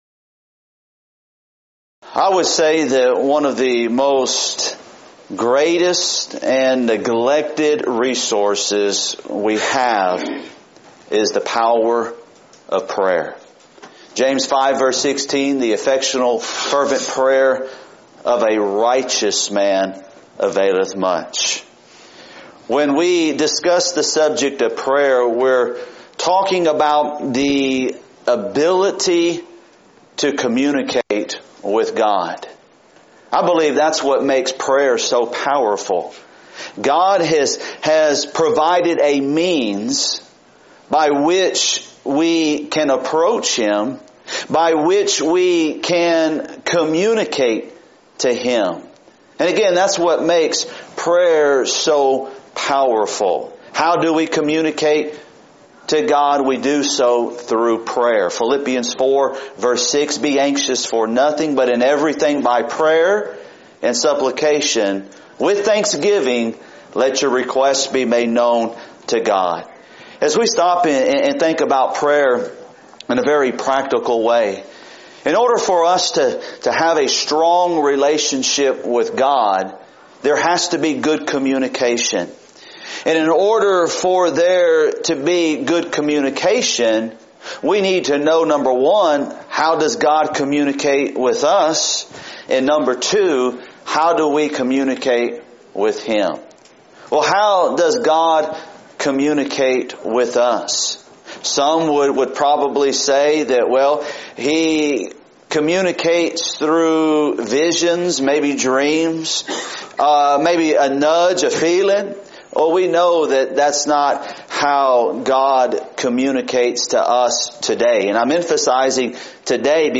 Preacher's Workshop
If you would like to order audio or video copies of this lecture, please contact our office and reference asset: 2018FocalPoint49